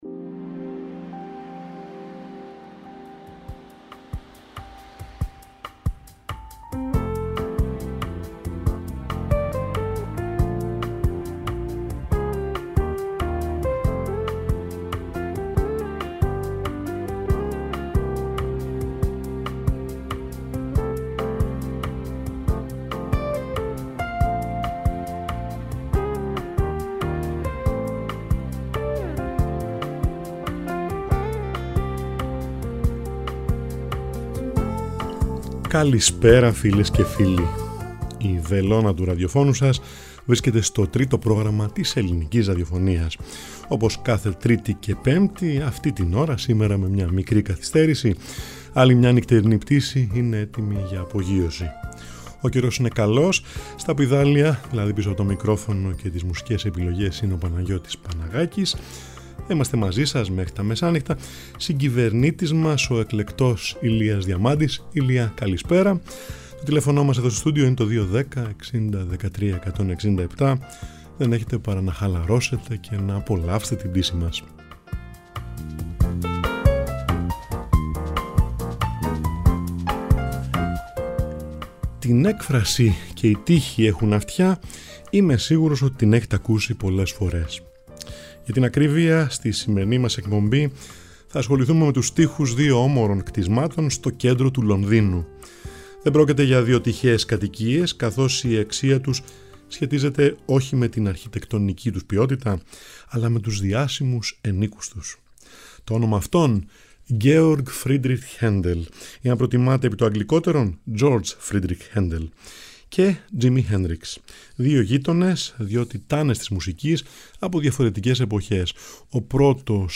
Νυχτερινη Πτηση Μουσική